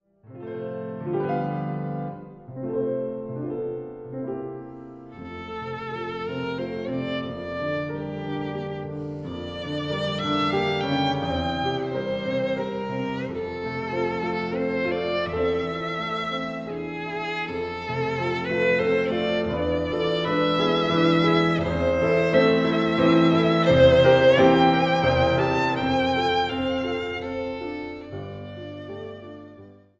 Sonate für Violine und Klavier Nr. 2 op. 21